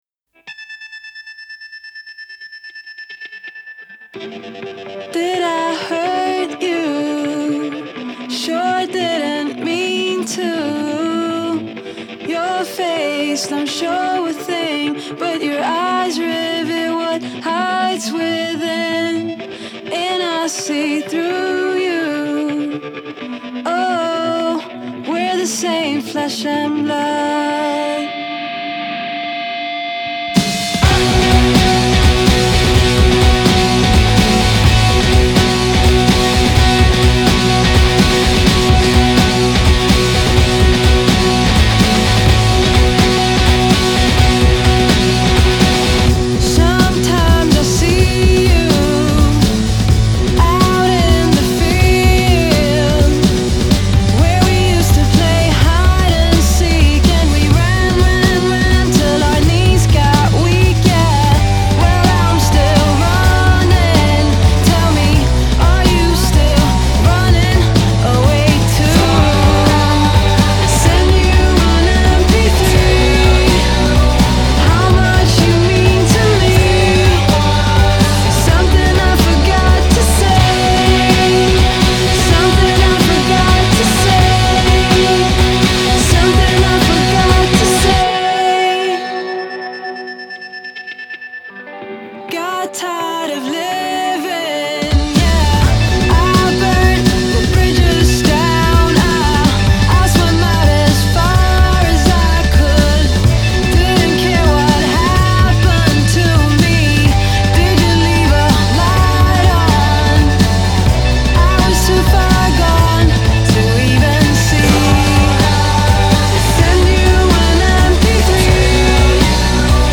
Genre: Indie Pop, Rock, Female Vocal